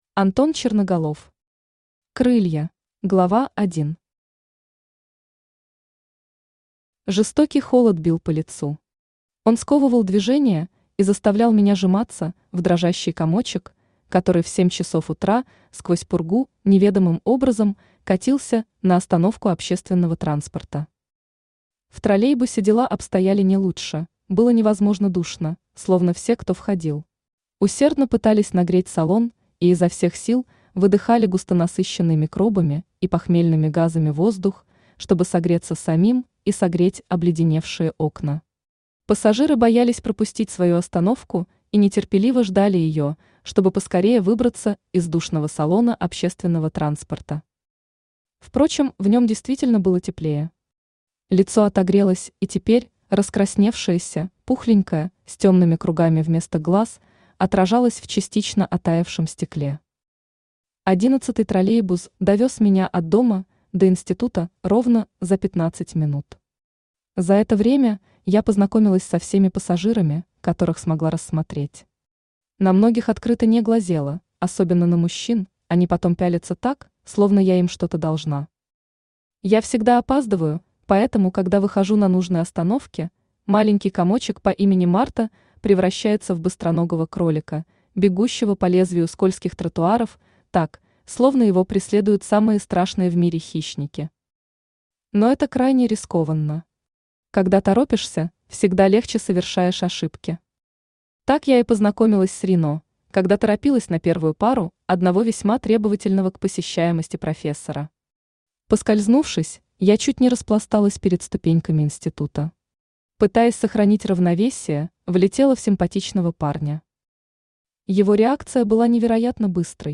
Aудиокнига Крылья Автор Антон Черноголов Читает аудиокнигу Авточтец ЛитРес.